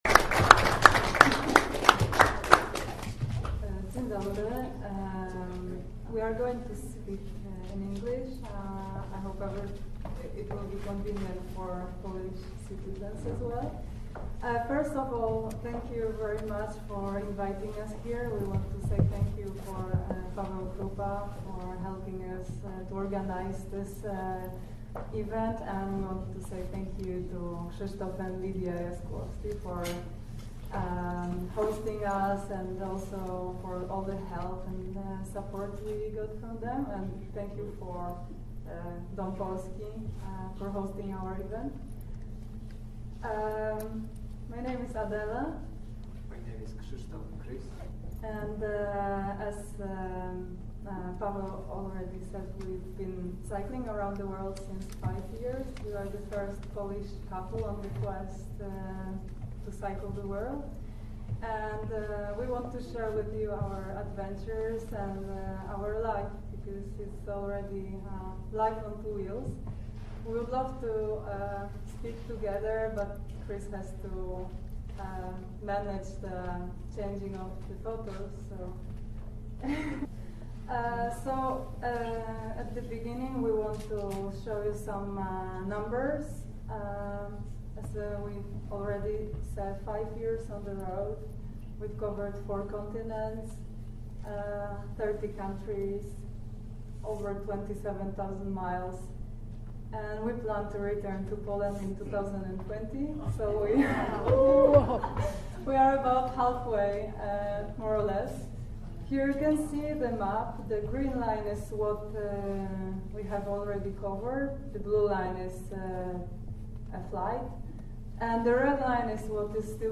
3 -go maja Dom Polski w Seattle gościł dwoje młodych ludzi ,którzy podróżują rowerami dookoła swiata.
Radio Wisła zaprasza na zapisaną prezentacje z tego wydarzenia.
(Nagrania na żywo- prezentacja w j. angielskim, rozmowa w j. polskim)